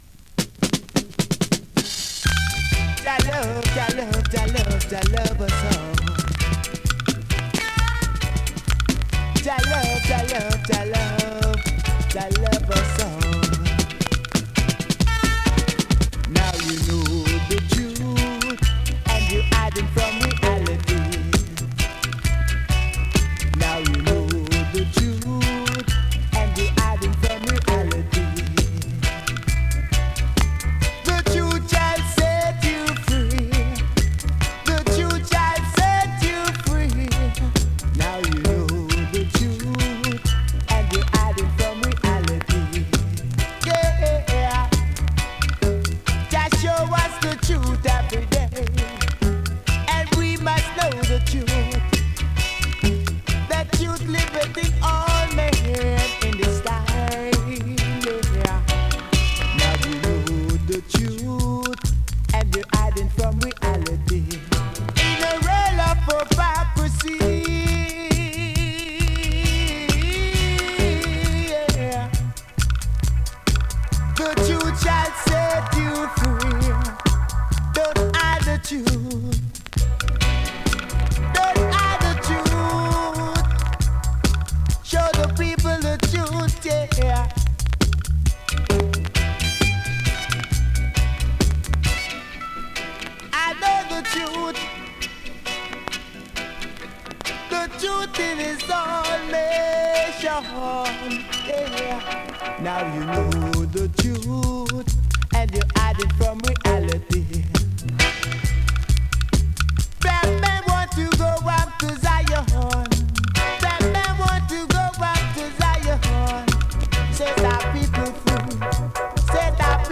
DANCEHALL!!
スリキズ、ノイズそこそこありますが